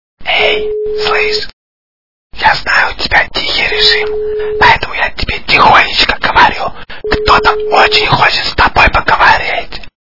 При прослушивании Говорящий телефон в безшумном режиме - Кто-то очень хочет с тобой поговорить качество понижено и присутствуют гудки.
Звук Говорящий телефон в безшумном режиме - Кто-то очень хочет с тобой поговорить